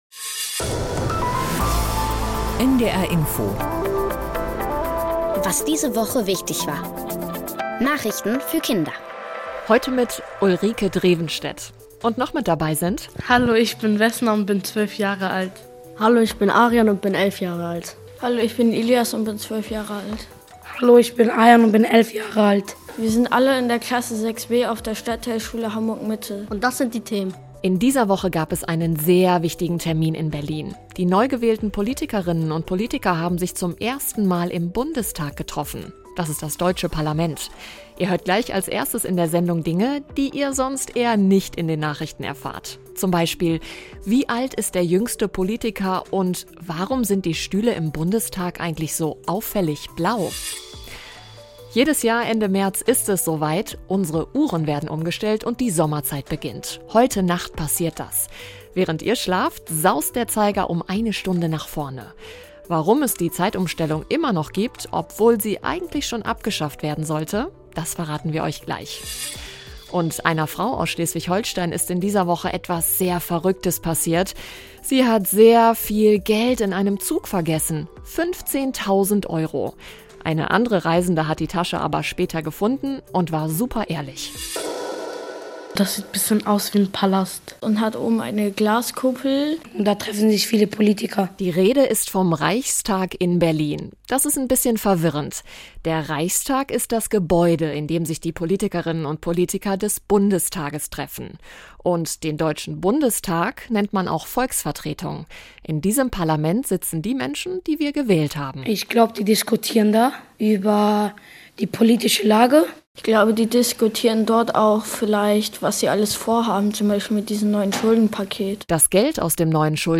Hier gibt's immer samstags die NDR Info Nachrichten der Woche für Kinder.